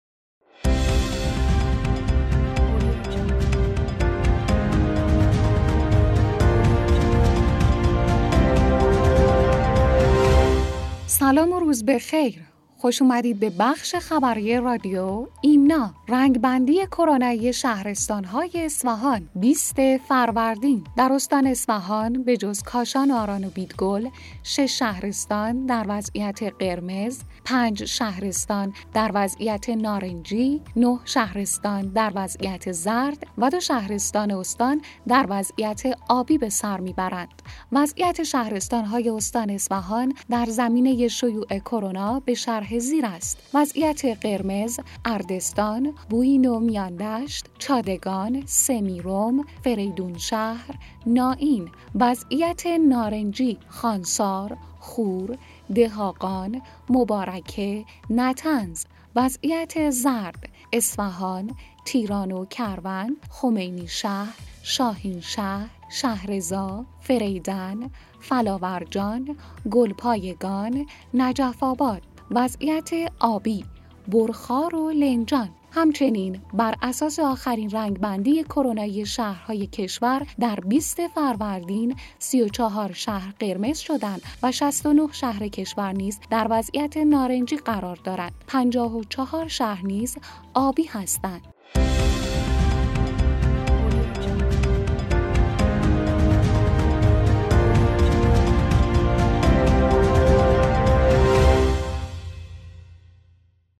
رادیو خبری ایمنا/